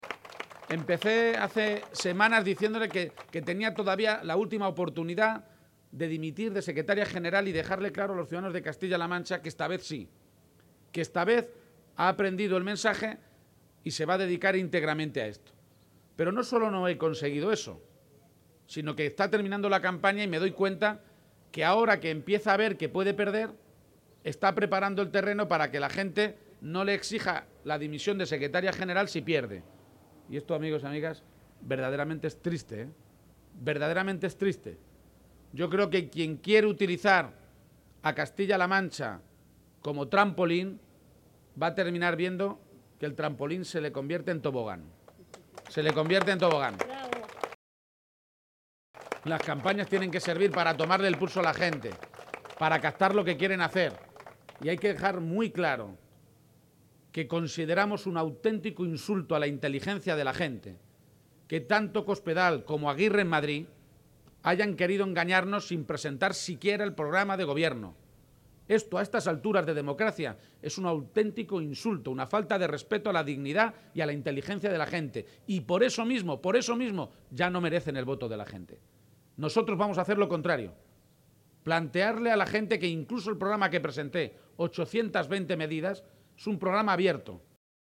En un acto público al aire libre en el municipio toledano de Corral de Almaguer, García-Page ha afirmado que se trata de “un insulto a la inteligencia y la dignidad de los ciudadanos”, porque la gente tiene derecho a saber lo que cada uno propone, “pero aún peor –ha añadido- es que Cospedal haya dicho “con todo el descaro del mundo” que lo va a presentar este viernes, el último día de campaña”.
Audios Page en Corral de Almaguer